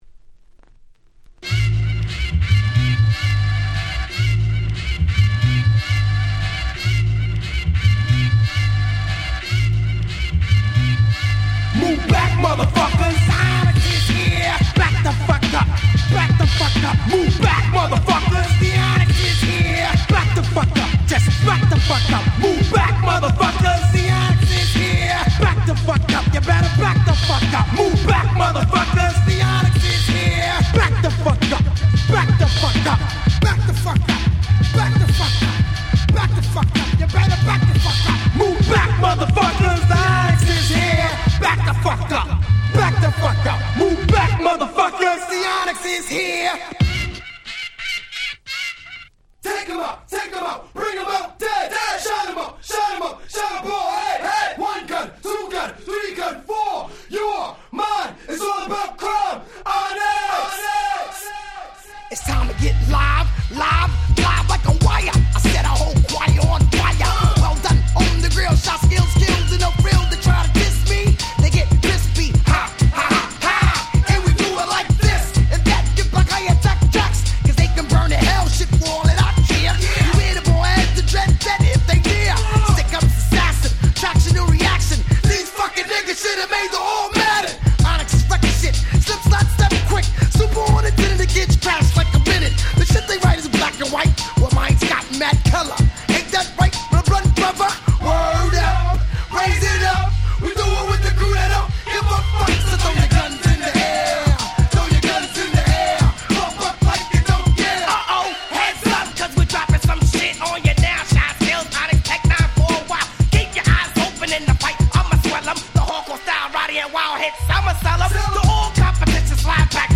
98' Smash Hit Hip Hop !!